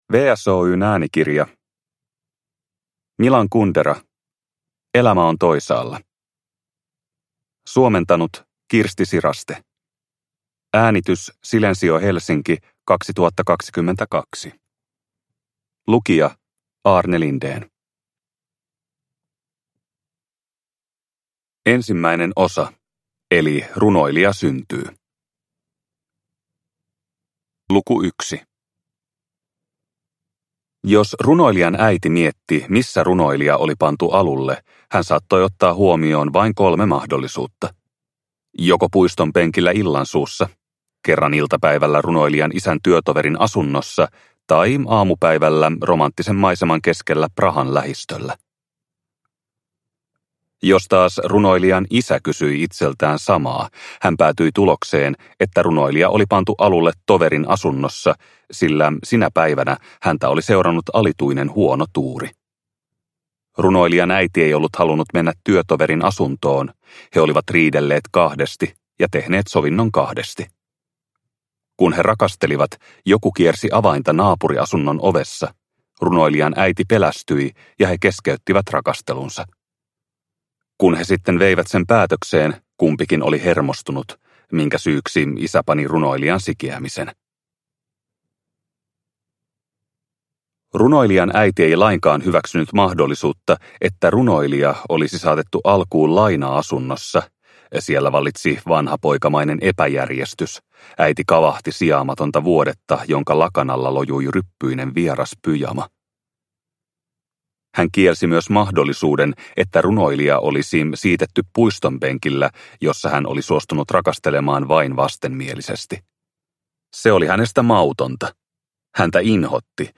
Elämä on toisaalla – Ljudbok – Laddas ner